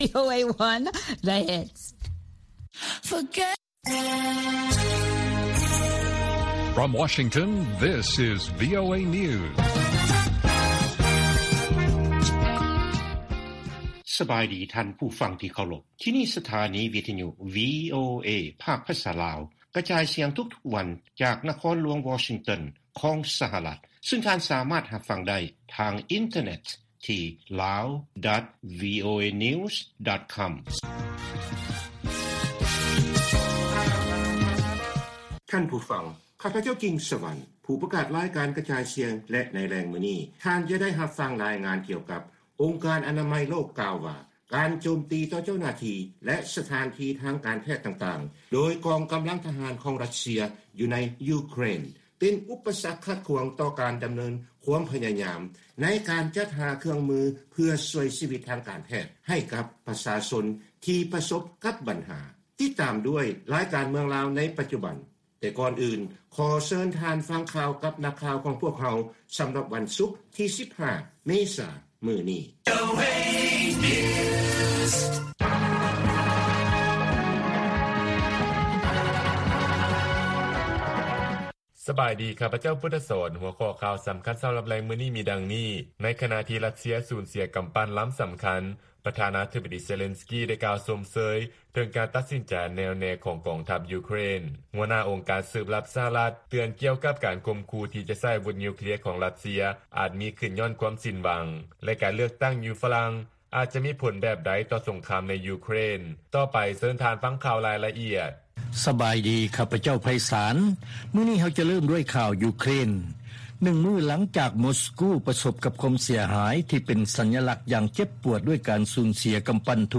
ວີໂອເອພາກພາສາລາວ ກະຈາຍສຽງທຸກໆວັນ: ຫົວຂໍ້ຂ່າວສໍາຄັນໃນມື້ນີ້ມີ: 1. ປະທານາທິບໍດີເຊເລນສ໌ກີ ໄດ້ກ່າວຊົມເຊີຍກອງທັບຢູເຄຣນ, 2. ຫົວໜ້າອົງການສືບລັບສະຫະລັດເຕືອນກ່ຽວກັບການຂົ່ມຂູ່ທີ່ຈະໃຊ້ອາວຸດນິວເຄລຍຂອງຣັດເຊຍ ‘ອາດມີຂຶ້ນຍ້ອນຄວາມສິ້ນຫວັງ, ແລະ 3. ການເລືອກຕັ້ງຢູ່ຝຣັ່ງ ອາດຈະມີຜົນແບບໃດຕໍ່ສົງຄາມໃນຢູເຄຣນ.